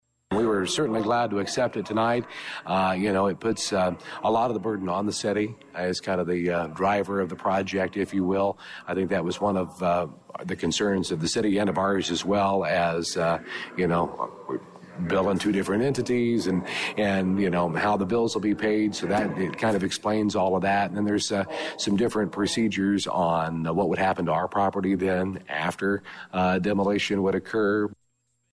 County Board Chairman Larry Baughn thinks it is win-win for both parties….